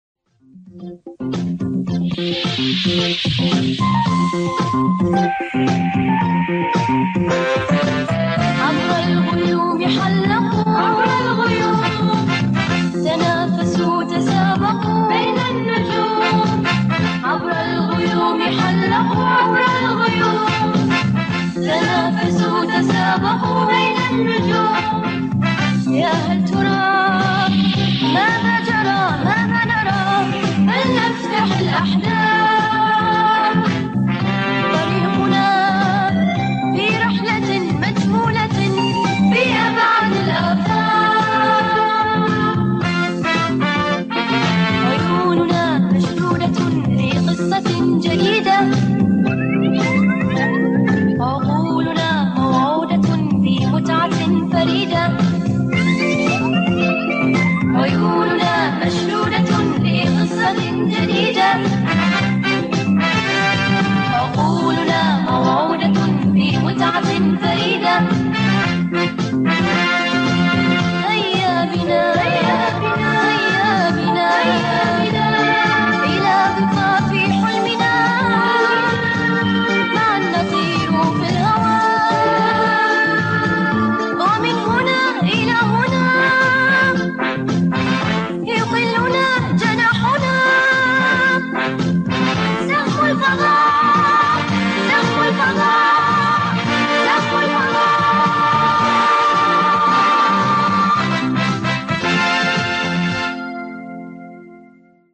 سهم الفضاء الجزء 1 - الحلقة 1 مدبلجة